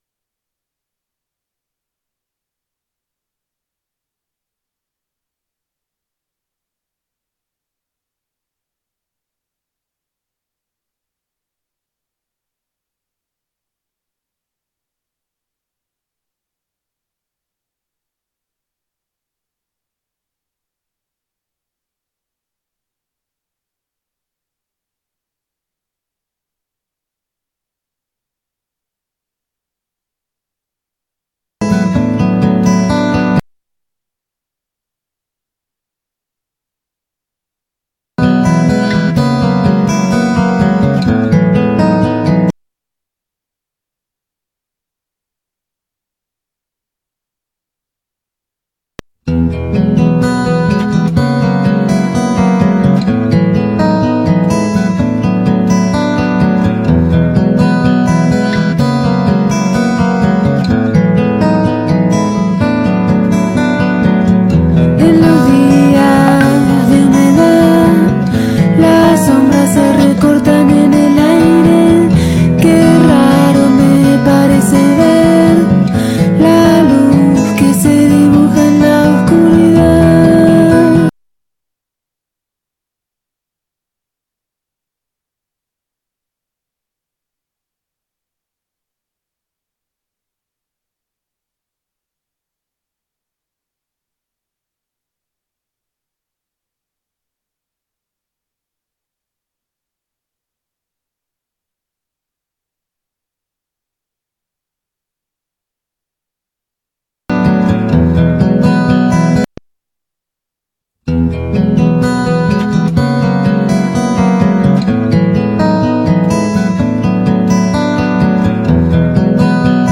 La emoción de Cadena 3: así fue la transmisión del despegue de Artemis II - La Argentina, hoy - Mundo